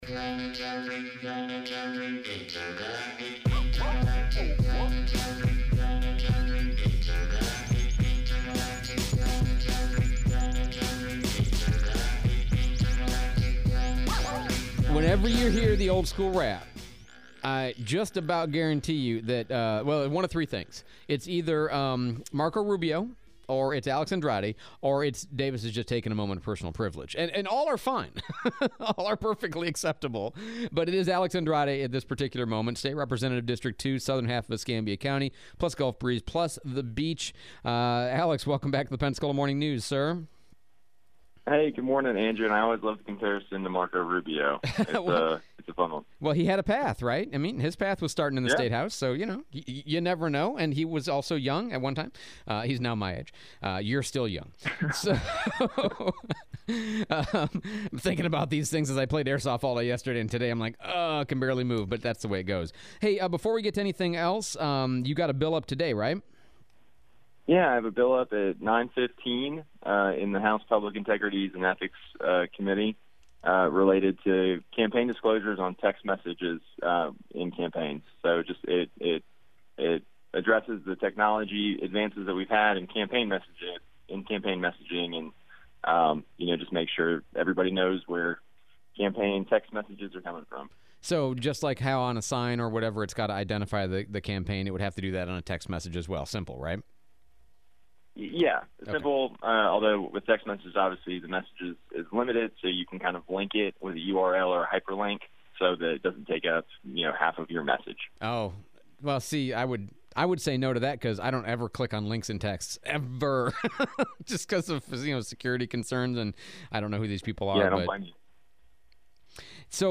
State Representative Alex Andrade joins the Pensacola Morning News to discuss his bill filed that would force text messages from campaigns to put within the text where the text is originating from, the Bright Futures bill that is currently lacking a House companion, and bills being filed that are worth discussing.